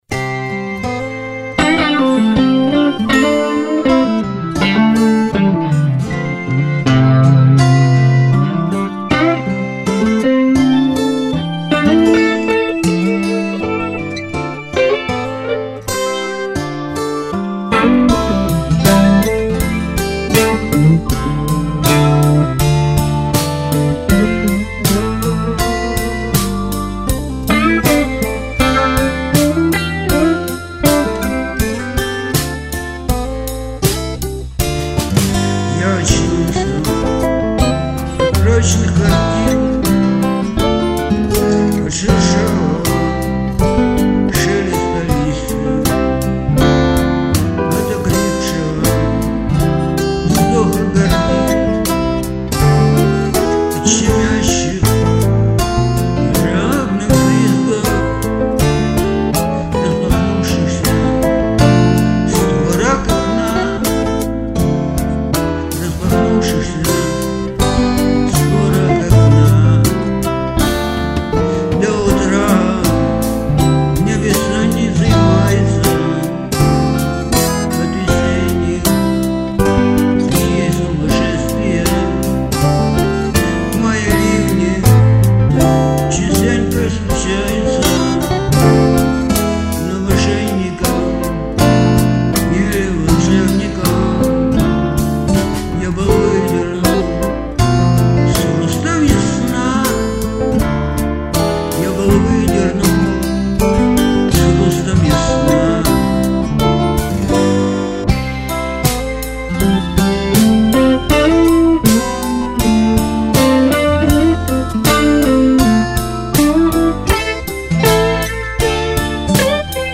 • Жанр: Блюз